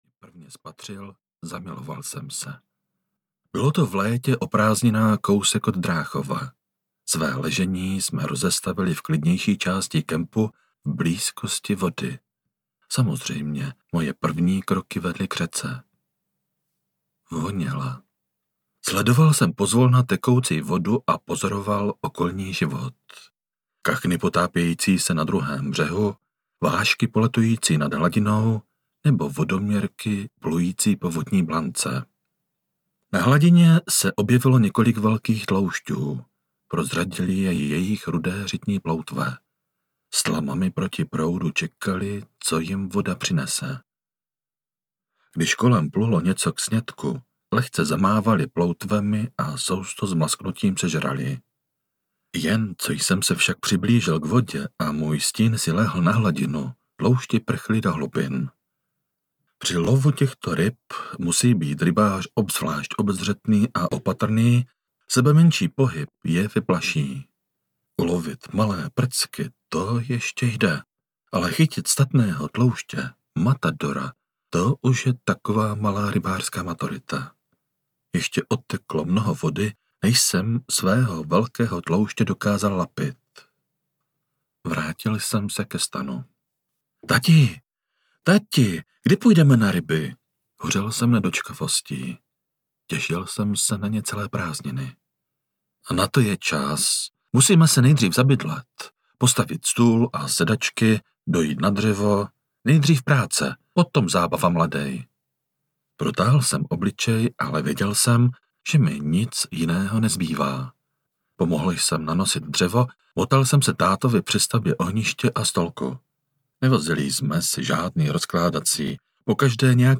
Rybolov v srdci audiokniha
Ukázka z knihy